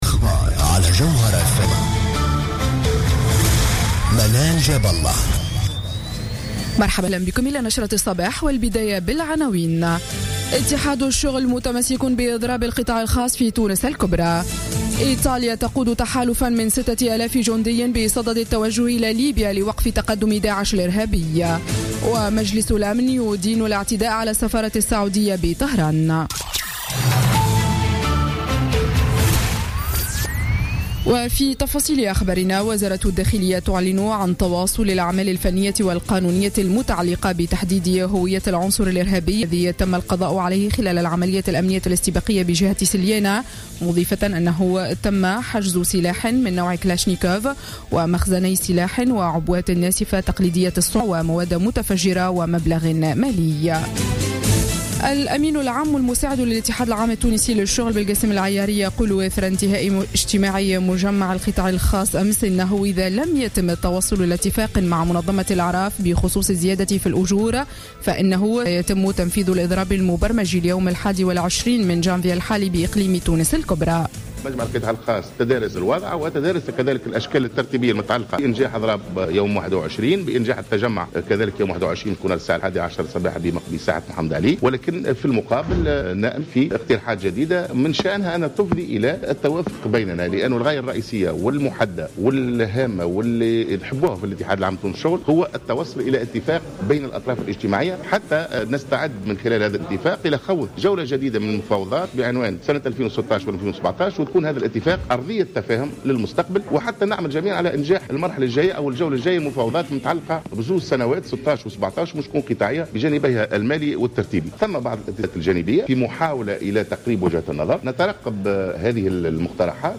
نشرة أخبار السابعة صباحا ليوم الثلاثاء 5 جانفي 2016